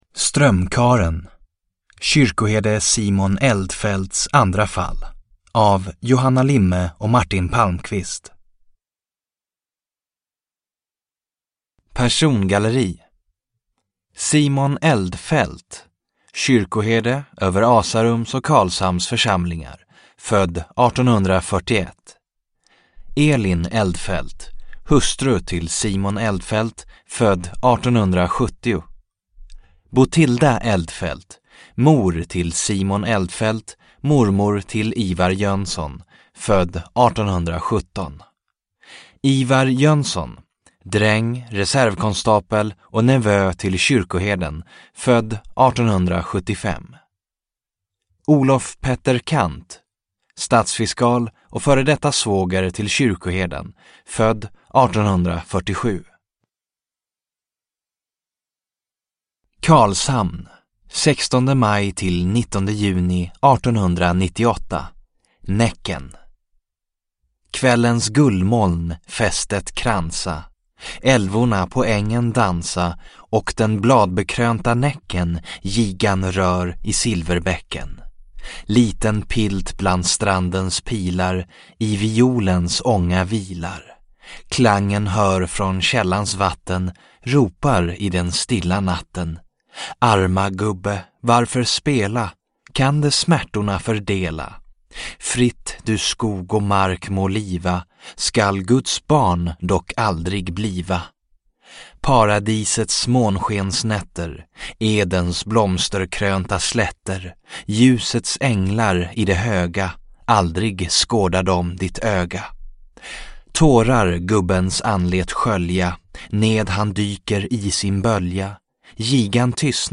Strömkarlen – Ljudbok – Laddas ner